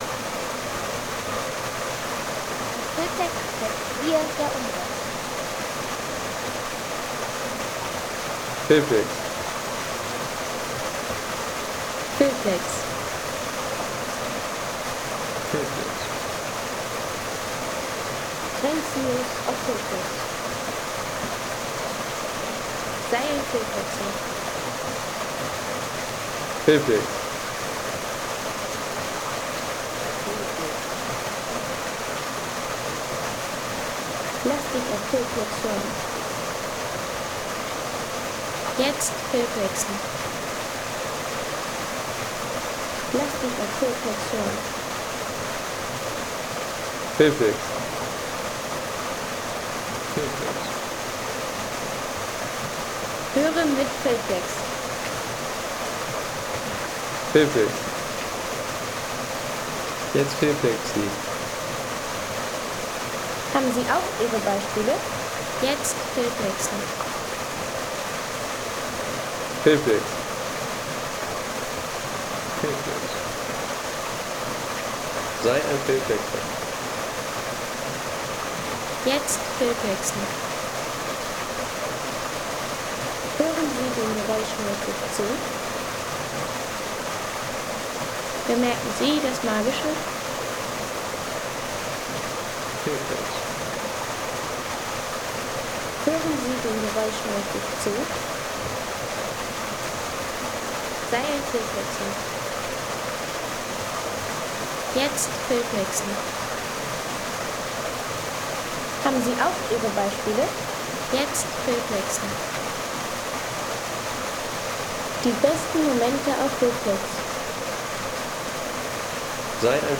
Kaskaden-Wasserfall Soundeffekt für Film und Naturszenen
Kaskaden-Wasserfall Soundeffekt | Kraftvolle alpine Wasseratmosphäre
Kraftvolle Wasserfallatmosphäre aus dem Tal der Stuibenfälle mit strömendem Wasser und tiefer Naturkulisse.